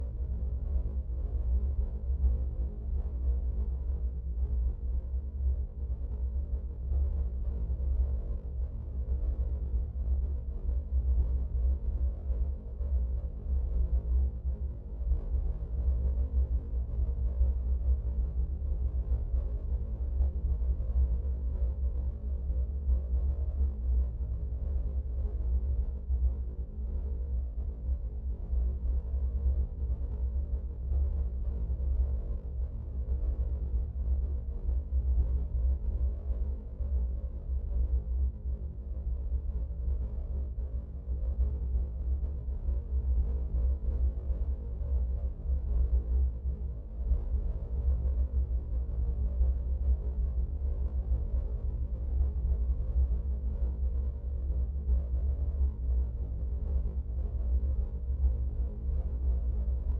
Sci-Fi Sounds / Hum and Ambience
Low Rumble Loop 2.wav